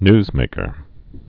(nzmākər, nyz-)